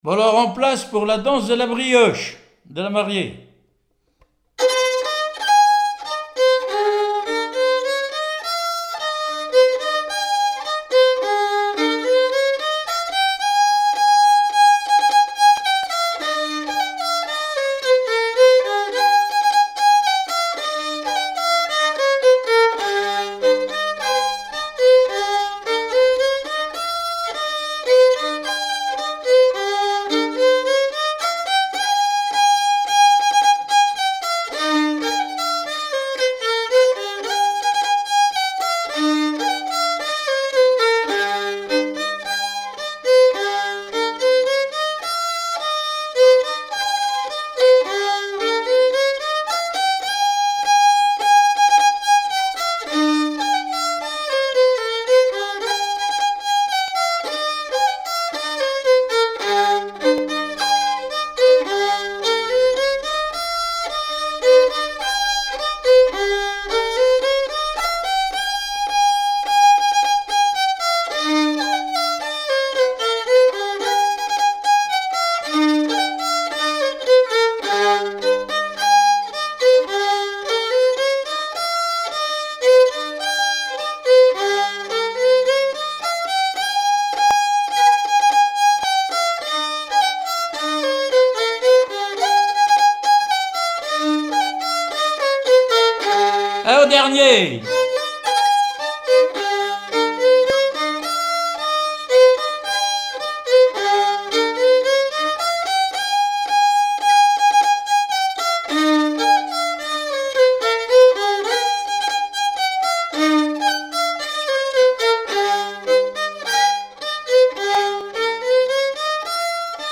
Mémoires et Patrimoines vivants - RADdO est une base de données d'archives iconographiques et sonores.
Chants brefs - A danser
danse : branle
Témoignages et musiques
Pièce musicale inédite